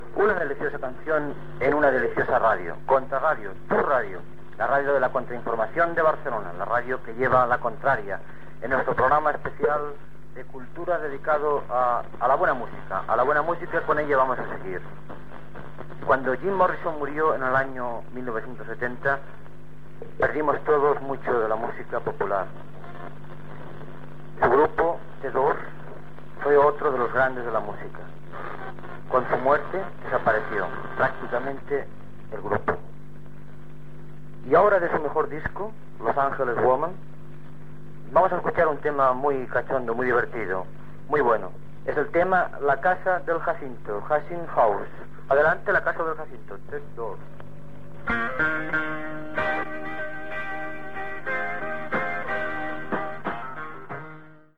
c9e0aa000fb2ef91e95cbc61cd052a54e6c311e5.mp3 Títol Contrarradio Emissora Contrarradio Titularitat Tercer sector Tercer sector Lliure Descripció Presentació d'un tema musical. Gènere radiofònic Musical